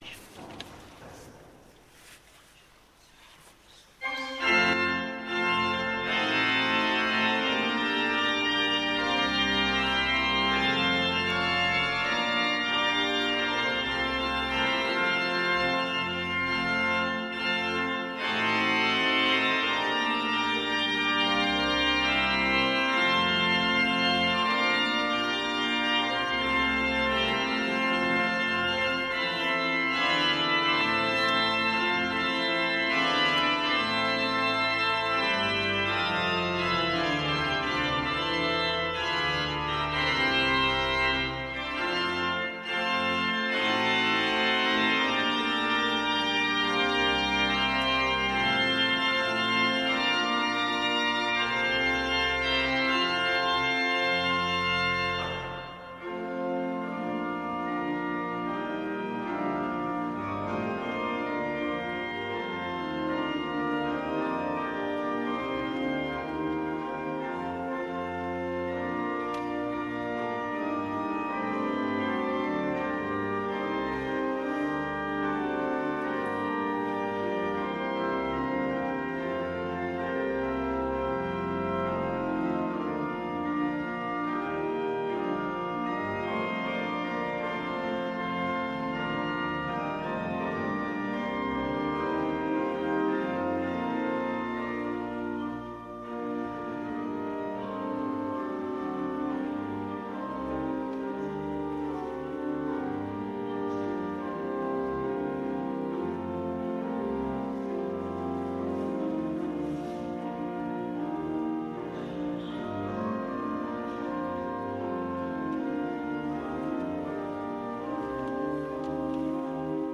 Le concert de 2011 Le concert de 2012 Videos : - Introduction, le projet :
concert_2012.mp3